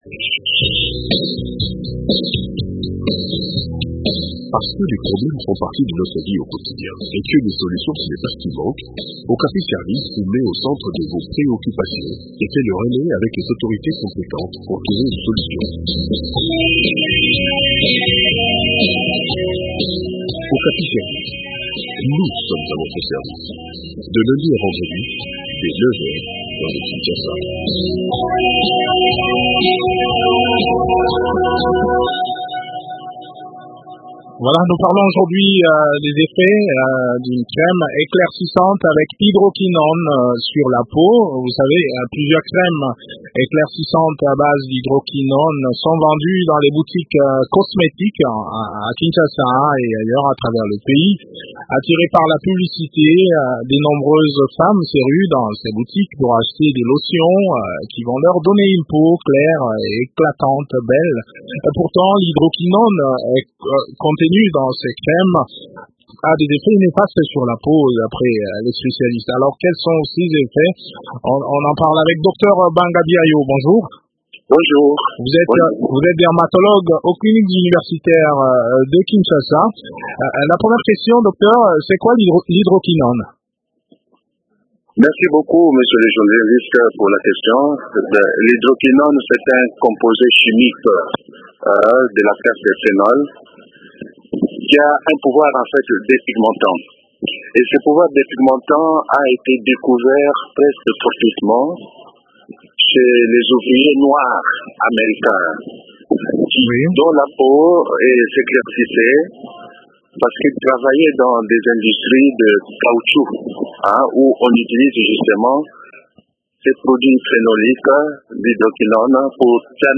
De plus amples détails dans cet entretien